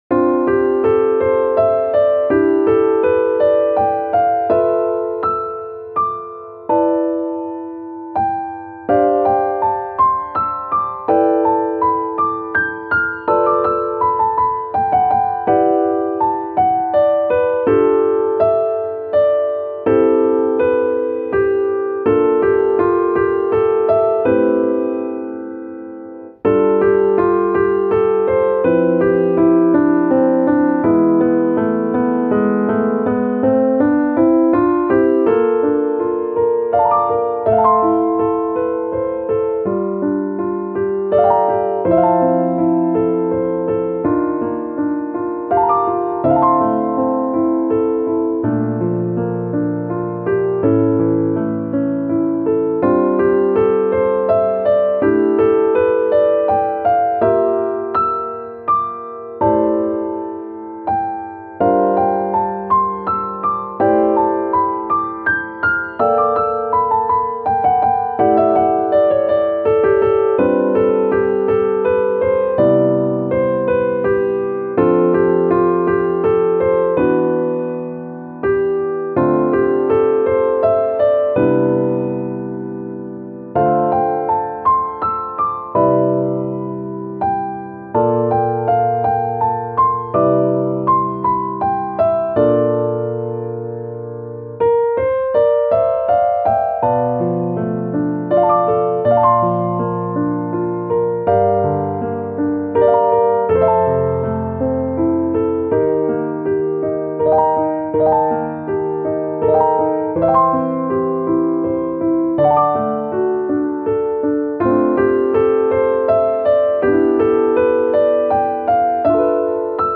ogg(L) - しっとり 不思議 安らぎ
白い雲にふわふわと守られているような安心感。